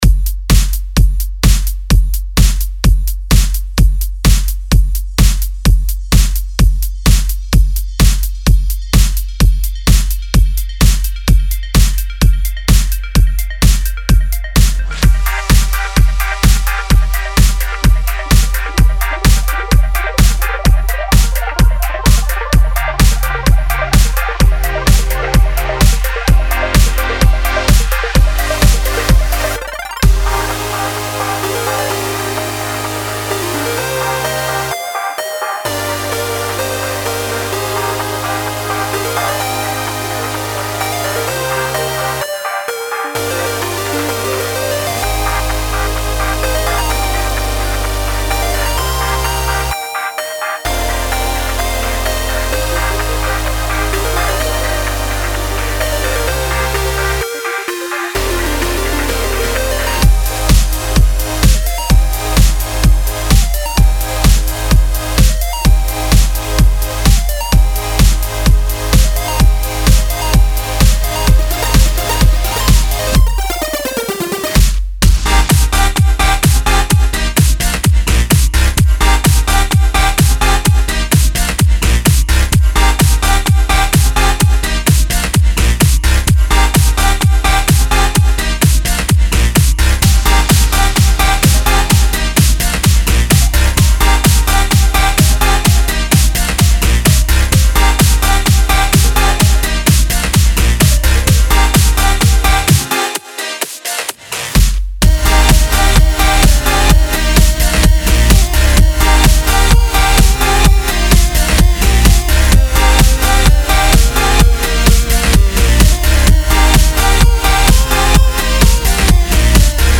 electro house song